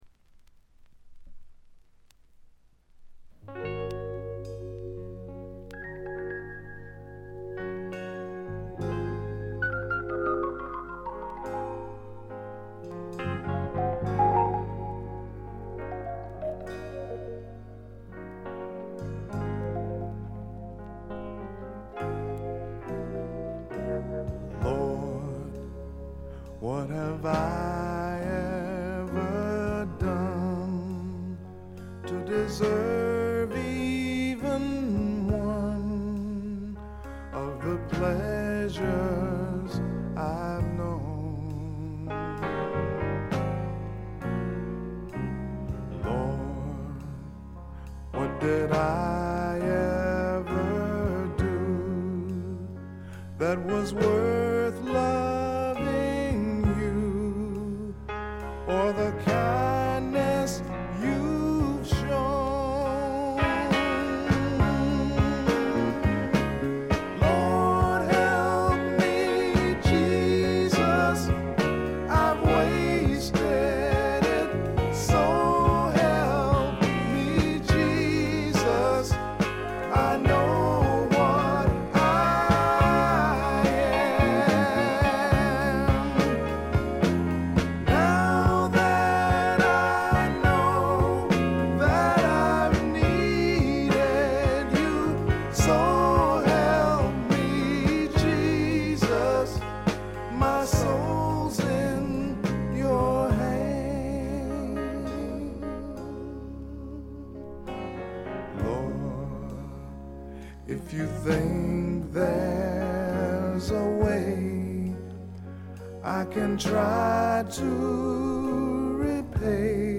これ以外はところどころで軽微なチリプチが少々。
フォーキーなアコースティック・グルーヴが胸を打つ名盤。
試聴曲は現品からの取り込み音源です。